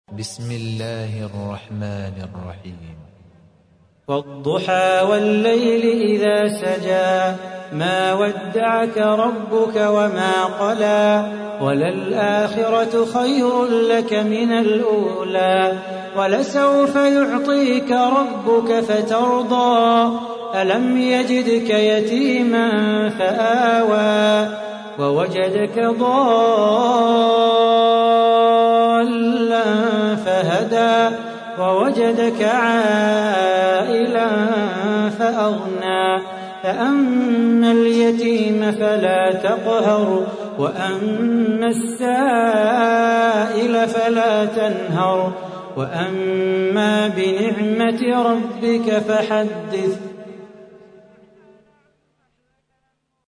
تحميل : 93. سورة الضحى / القارئ صلاح بو خاطر / القرآن الكريم / موقع يا حسين